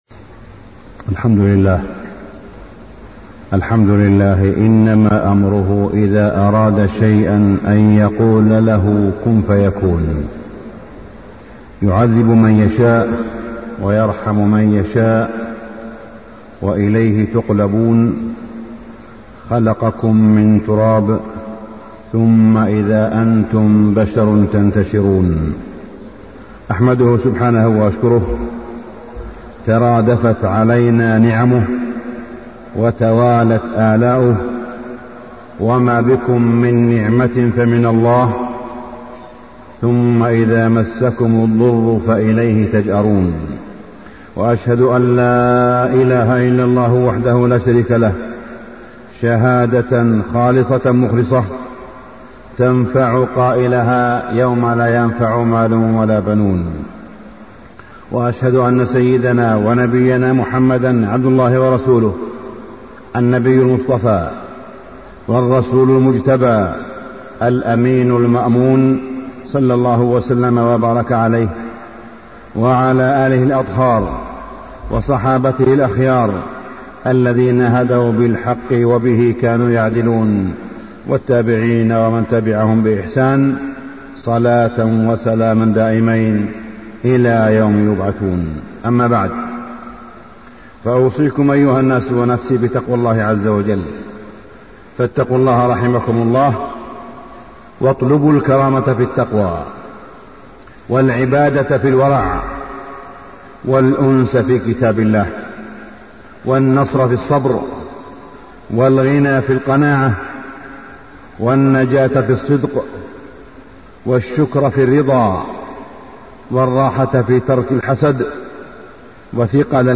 تاريخ النشر ١ ربيع الأول ١٤٣٢ هـ المكان: المسجد الحرام الشيخ: معالي الشيخ أ.د. صالح بن عبدالله بن حميد معالي الشيخ أ.د. صالح بن عبدالله بن حميد خطورة الغيبة